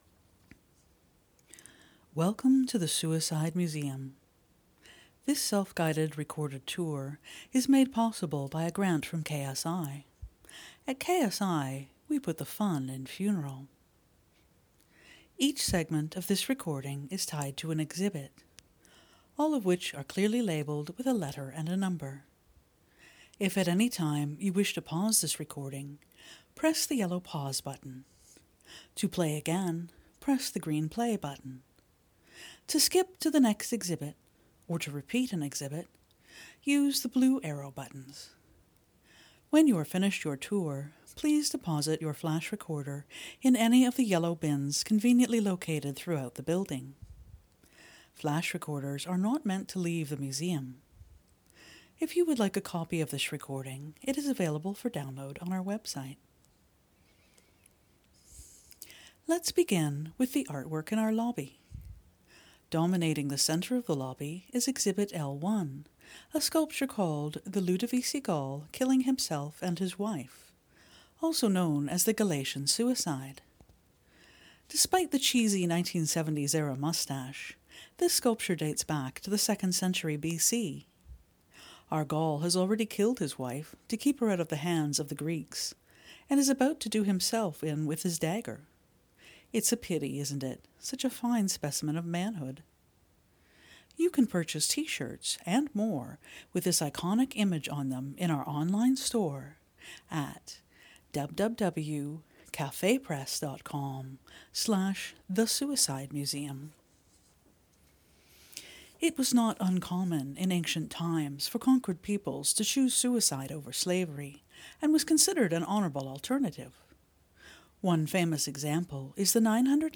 theSuicideMuseum.com_GuidedTour.mp3